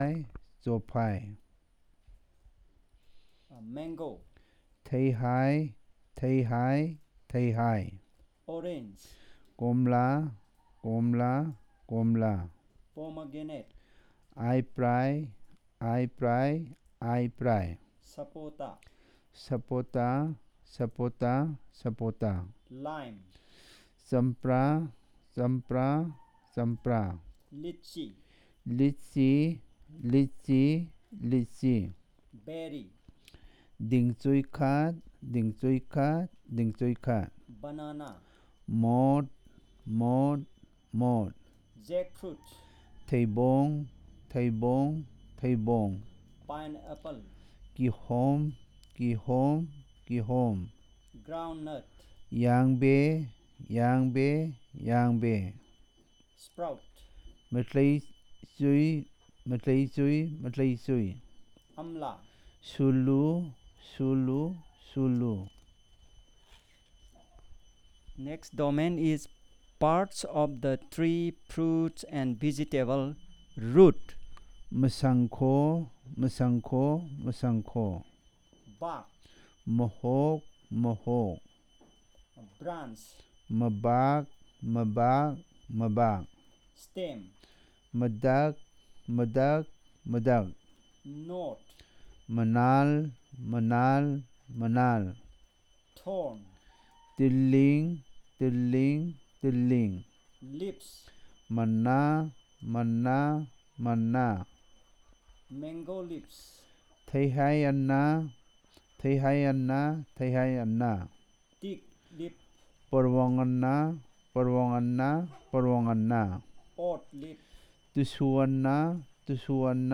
Elicitation of words about fruits and seeds, parts of tree fruit and vegetables
NotesThis is the continuous elicitation of some words of fruits & seeds, and parts of tree fruit and vegetables.